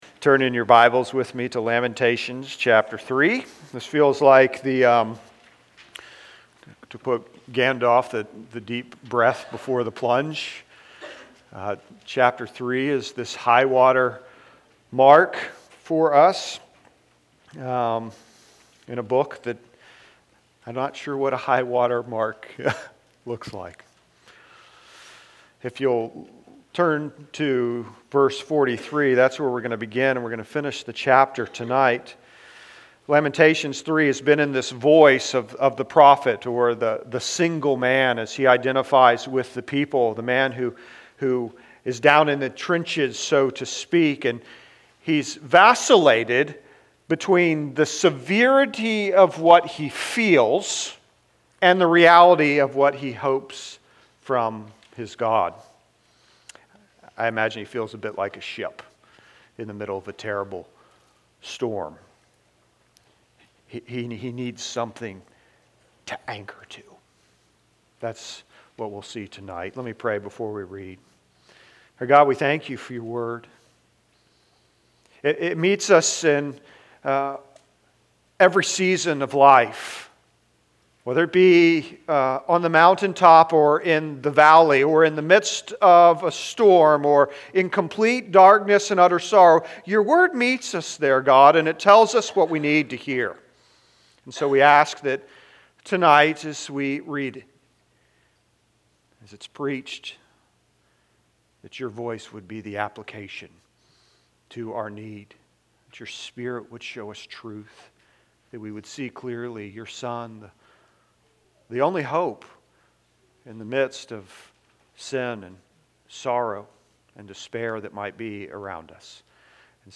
The Anchor Of Hope (Lamentations 3:43-66) Sermons And Lessons From All Saints Presbyterian Church podcast